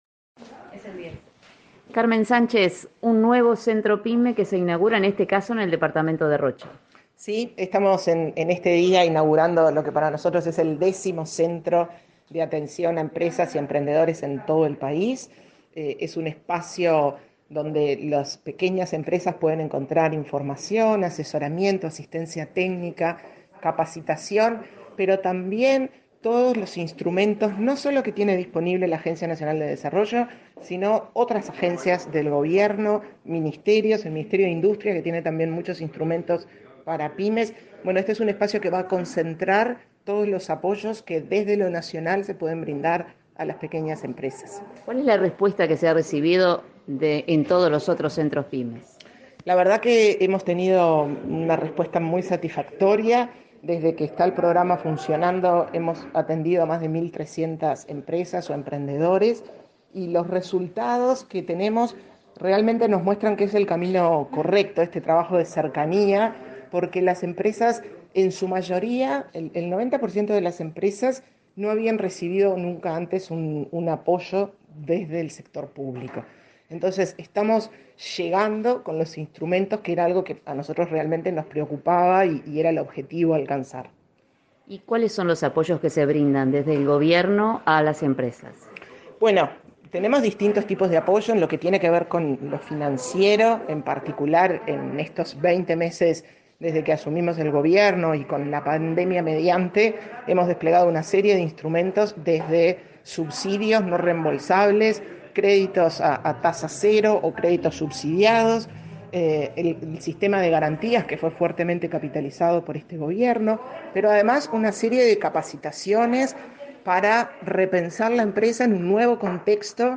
Declaraciones de la presidenta de ANDE, Carmen Sánchez, a Comunicación Presidencial
El programa de Centros Pymes de la Agencia Nacional de Desarrollo (ANDE) brinda herramientas para fomentar el crecimiento de emprendimientos, micro, pequeñas y medianas empresas. En la oportunidad, inauguró un centro en Rocha. Al finalizar el acto, Carmen Sánchez efectuó declaraciones a Comunicación Presidencial.